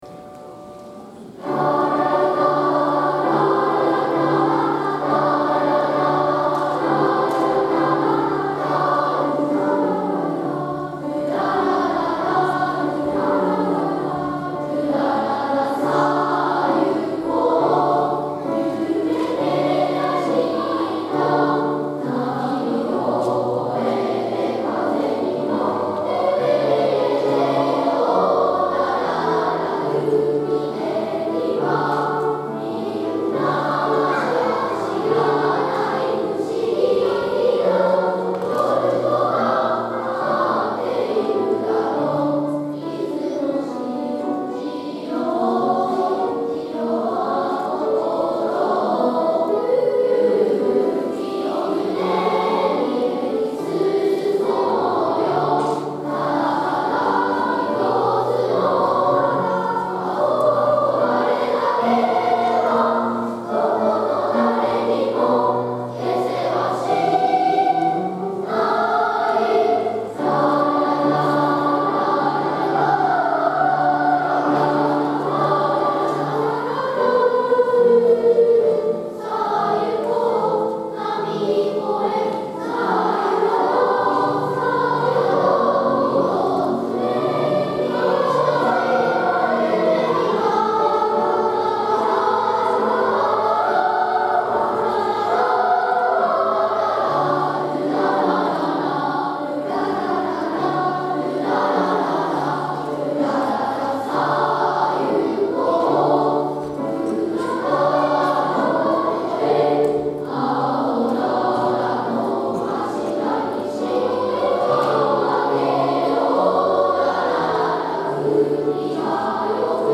今日は第１４回大空ふれあいコンサートです！
５・６年生（高学年チーム）　３部合唱「
高学年チームの学習では、自分のパートの音を確認するとともに他のパートの歌声を聴き、それぞれの音を大切にしながら、今年の高学年チームにしかつくれないハーモニーをつくってきました。